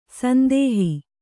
♪ sandēhi